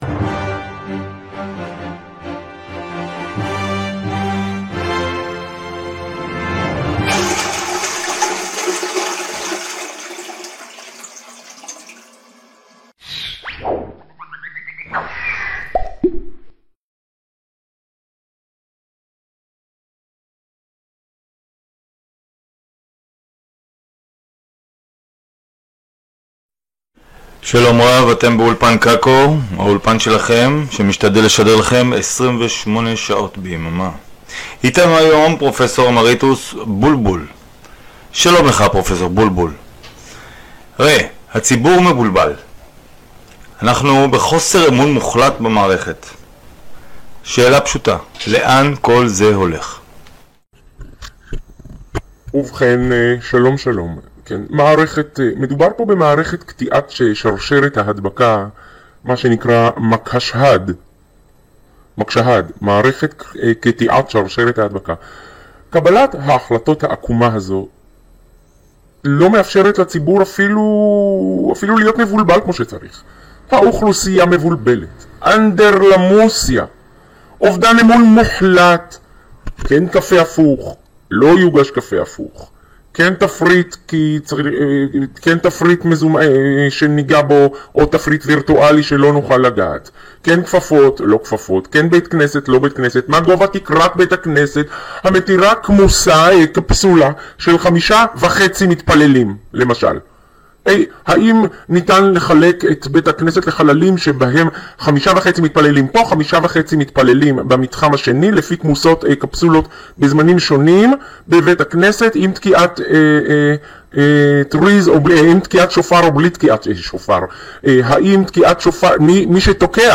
חדשות קקו: ראיון ראשון